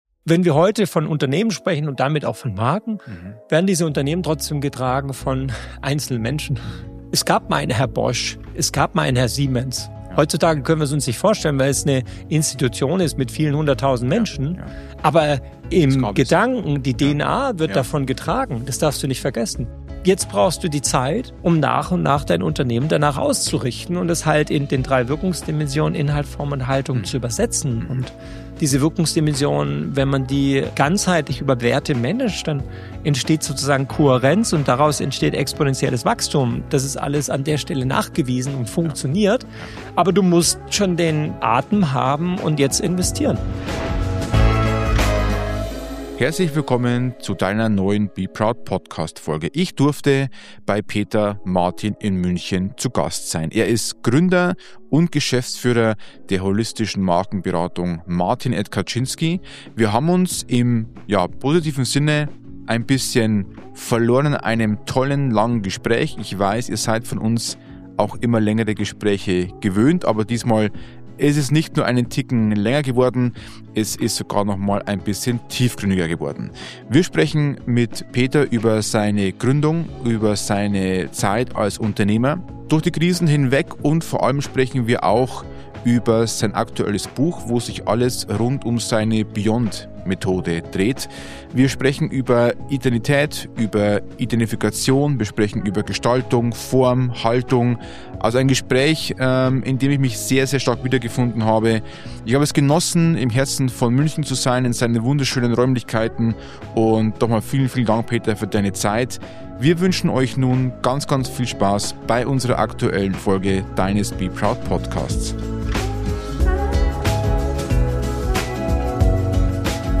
im Gespräch ~ Be Proud - people.culture.brand Podcast